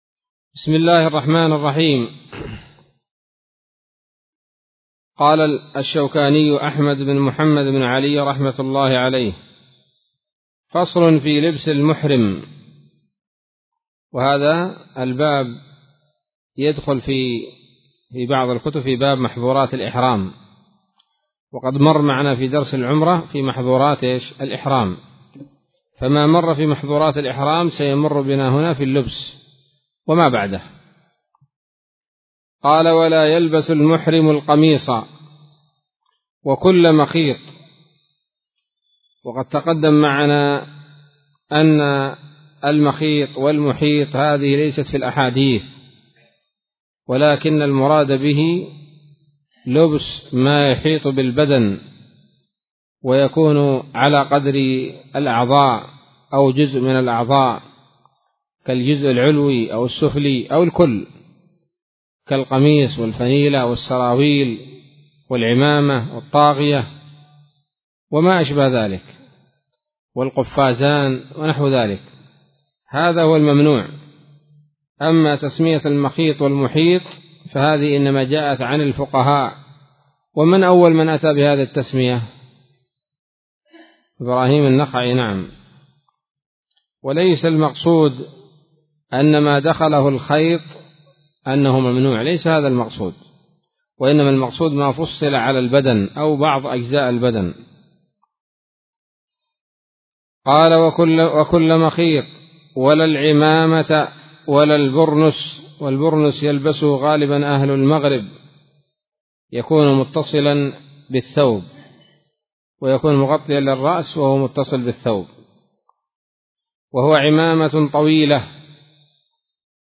الدرس الرابع من كتاب الحج من السموط الذهبية الحاوية للدرر البهية